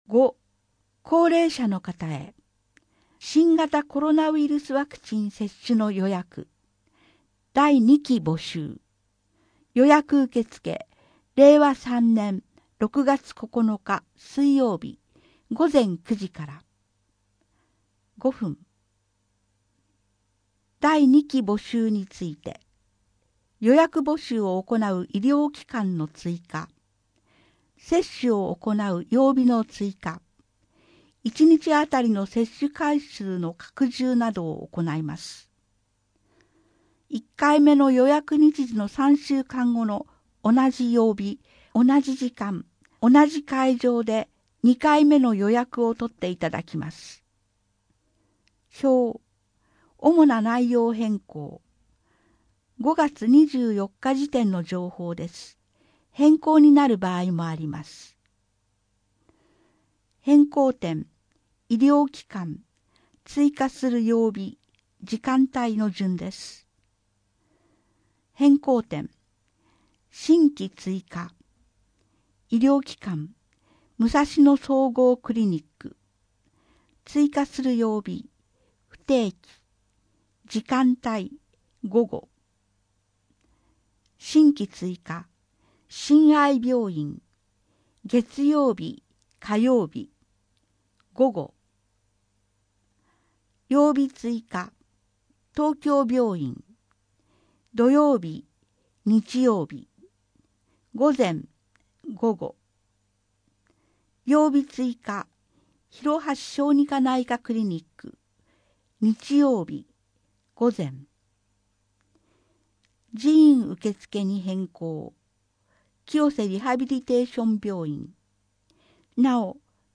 声の広報は清瀬市公共刊行物音訳機関が制作しています。